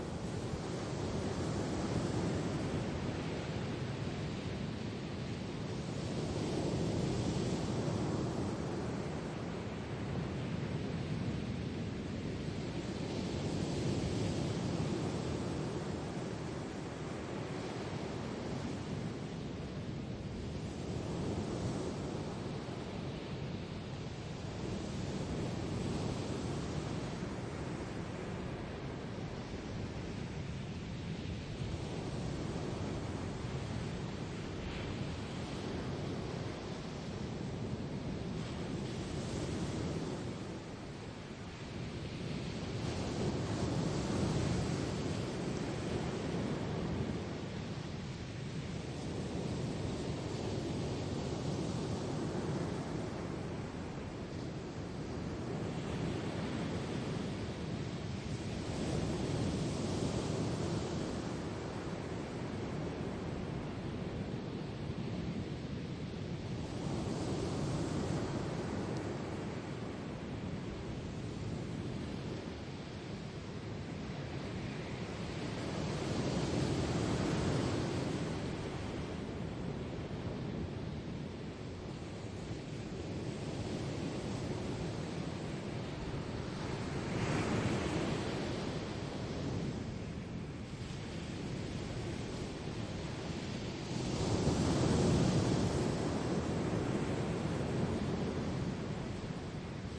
描述：从100米距离记录的破浪。可以听到一些引擎和鸟类 在波尔图附近的Lavadores海滩 录制双耳麦克风Soundman OKM
标签： 双耳 沙滩 海水边 海浪 大西洋 风暴 海洋 岩石 波浪 春天 现场录音 潮汐 海浪
声道立体声